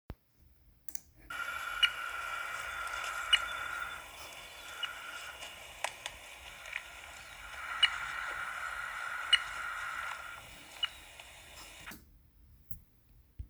VHF stands for Very High Frequency and is a form of telemetry that uses radio waves (a type of electromagnetic (EM) wave) of a frequency range considered to be “very high” (30-300 MHz) to transmit a signal that can be picked up by the receiver’s antenna. The antenna converts the EM wave into electrical currents which ultimately produces an audible pinging signal (Listen to the clip to hear what it sounds like!).
vhf.mp3